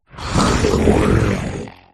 drednaw_ambient.ogg